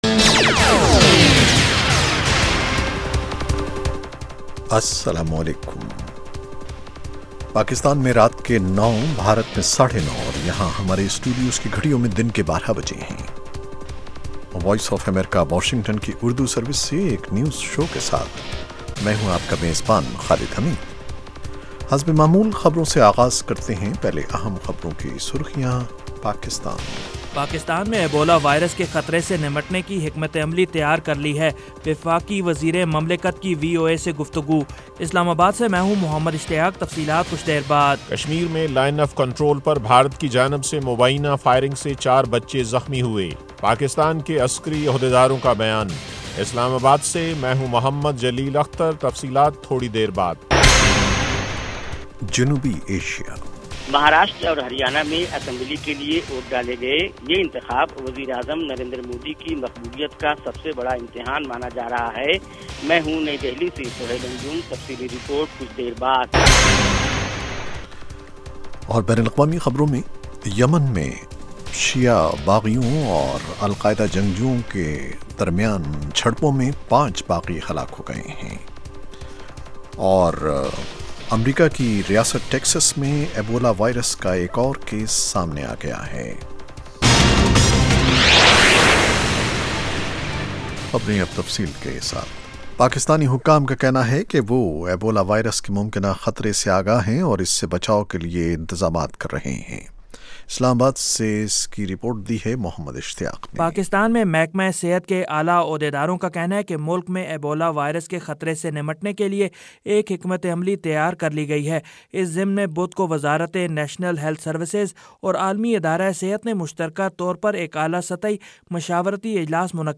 اس کے علاوہ انٹرویو، صحت، ادب و فن، کھیل، سائنس اور ٹیکنالوجی اور دوسرے موضوعات کا احاطہ۔